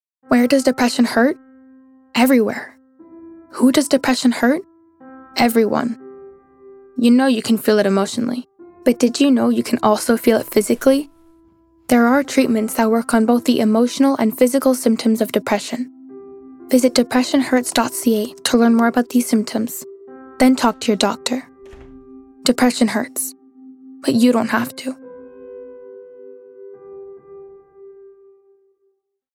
Publicité (2) - ANG